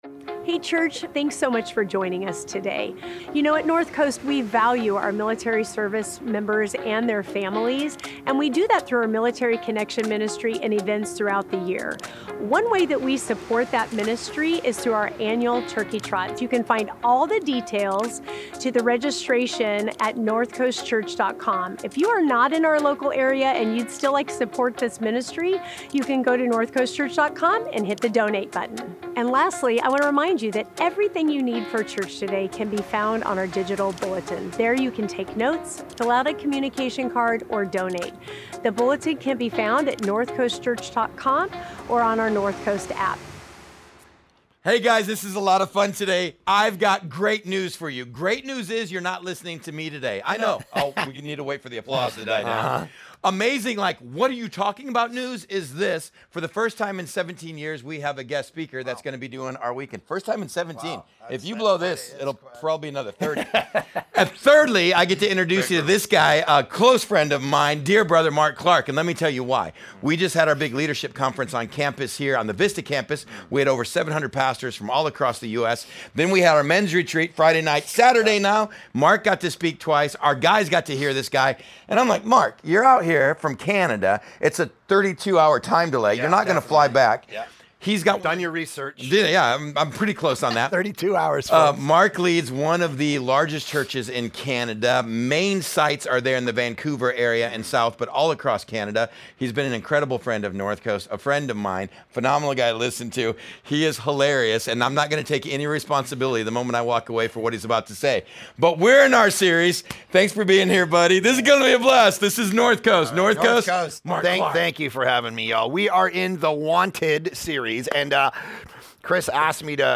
Wednesday Night Sermon